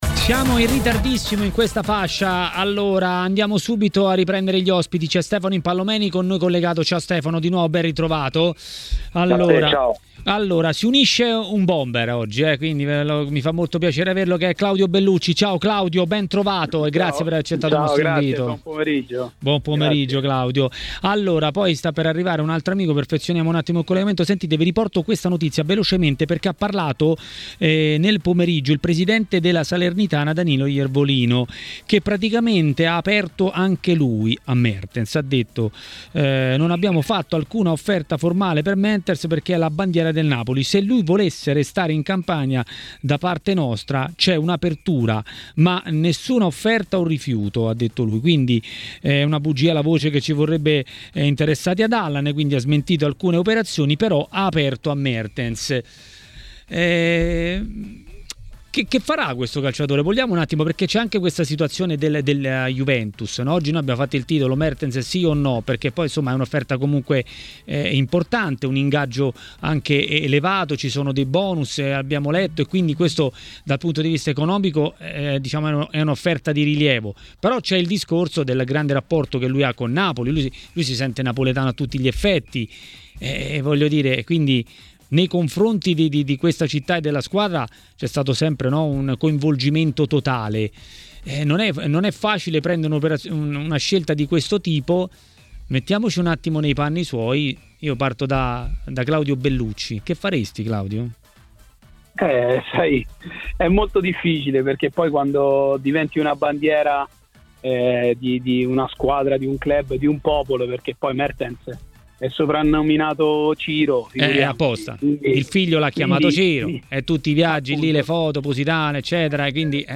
L'ex attaccante del Napoli Claudio Bellucci ha parlato del futuro di Dries Mertens ai microfoni di TMW Radio.